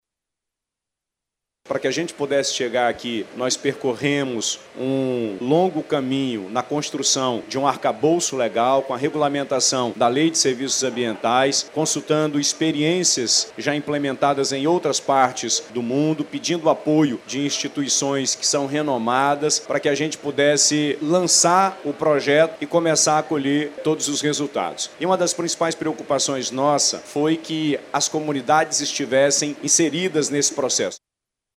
Sonora-Wilson-Lima-governador.mp3